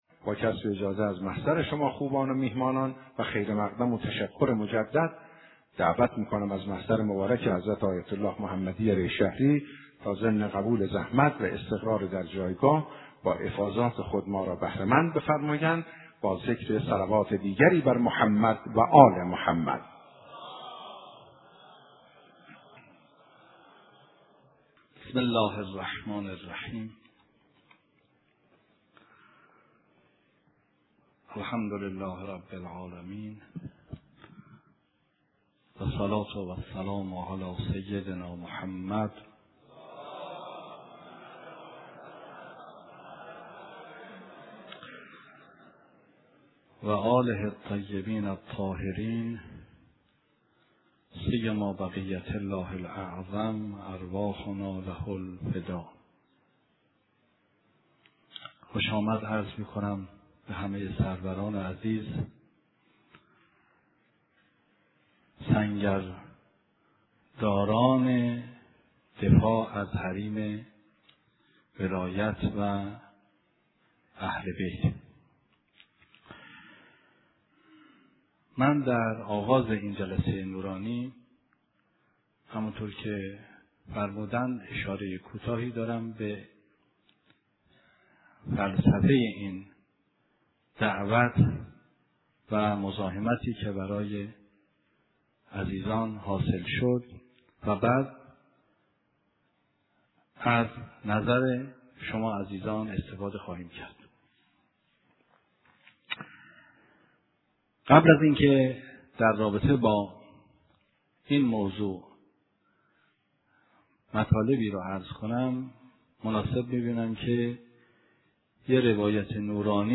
ديدار با جمعي از شعرا و ستايشگران اهل بيت عليهم السّلام